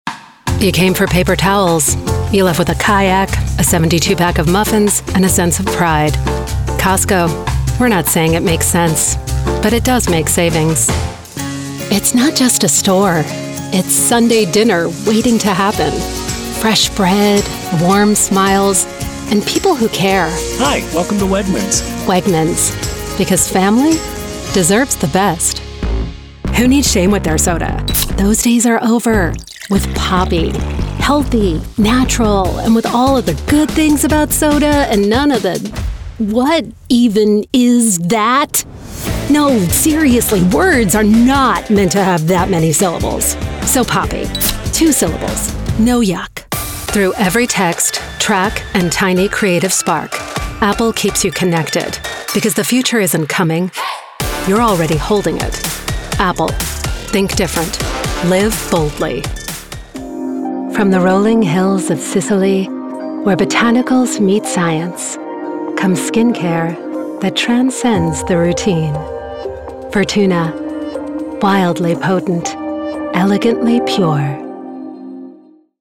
Never any Artificial Voices used, unlike other sites.
The Voice Realm represents professional and affordable American & Canadian voice talent with authentic North American accents suited to international voice castings, from small jobs to international campaigns.
Our voice over talent record in their professional studios, so you save money!
Adult (30-50) | Older Sound (50+)